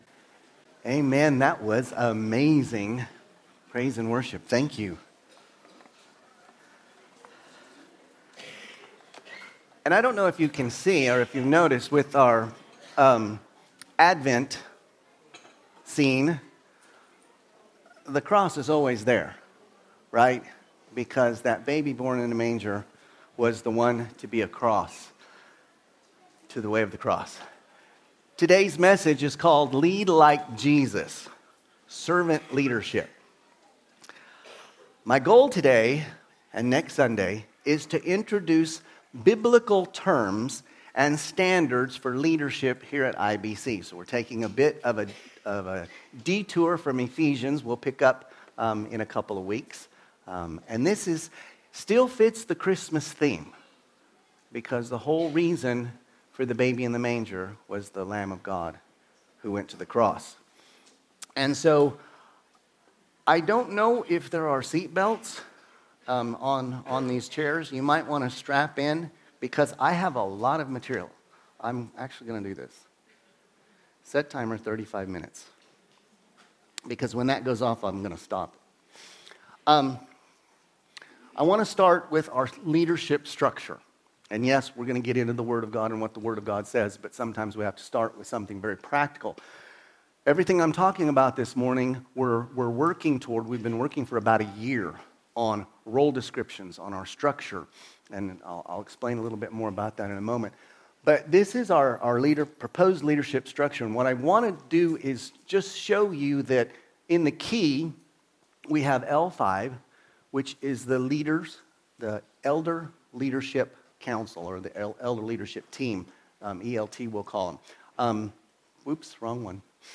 Sermons – Immanuel Baptist Church | Madrid
From Series: Sunday Service